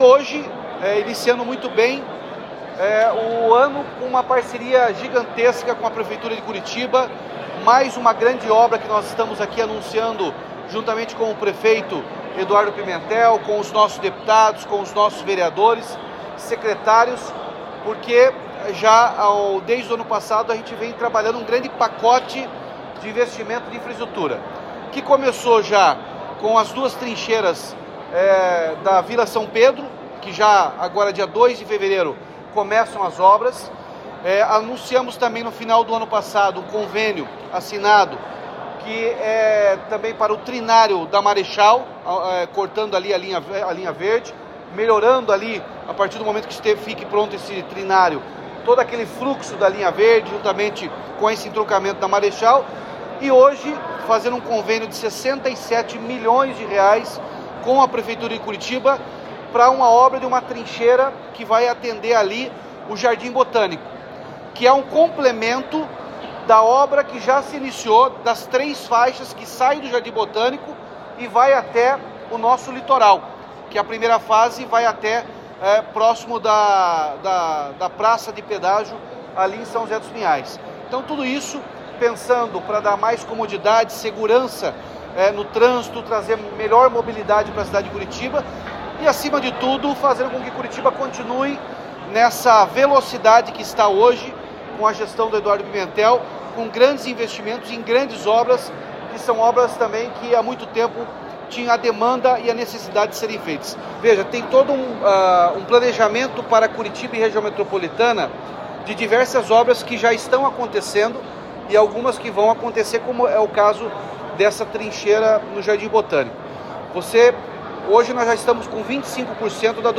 Sonora do governador Ratinho Junior sobre o investimento de investimento de R$ 67 milhões em trincheira no Jardim Botânico